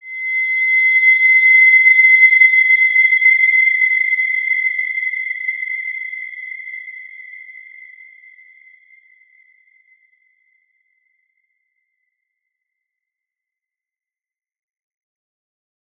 Wide-Dimension-C6-p.wav